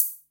MAM ADX1 analog single drums » sn3
描述：MAM ADX1 is a german made analog drumbrain with 5 parts, more akin to a Simmons/Tama drum synth than a Roland Tr606 and the likes. http
标签： adx1 analog drumbrain mam singleshot snare
声道立体声